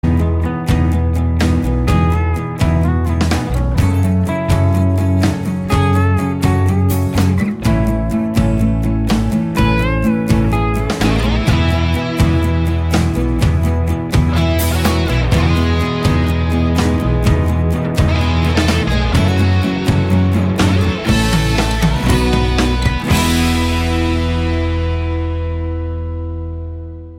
File Type : Mp3 ringtones